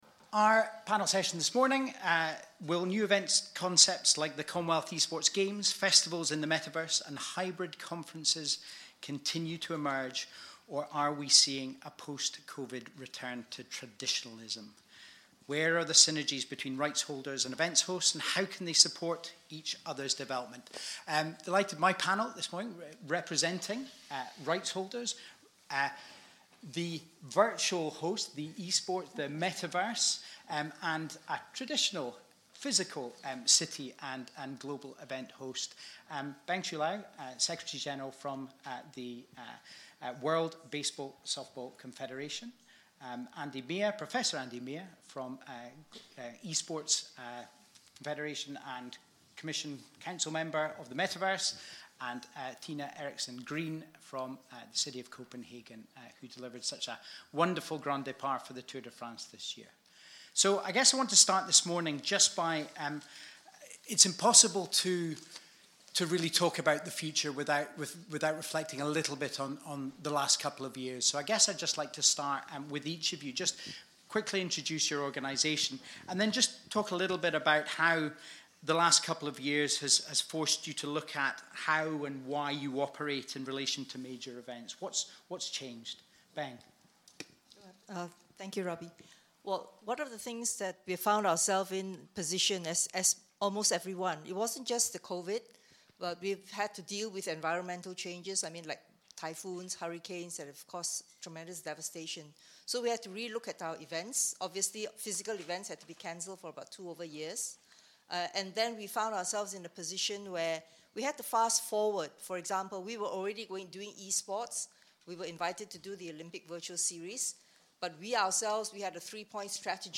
Host City 2022 Panel 2: New event concepts – disruption and synergy